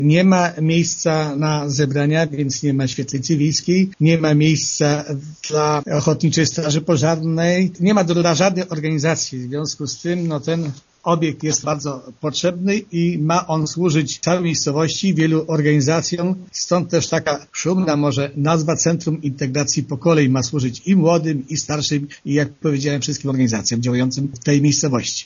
„To bardzo dobra wiadomość, ponieważ w Ryżkach, które są jedną z największych miejscowości w gminie takiego obiektu nie ma” - mówi zastępca wójta Wiktor Osik: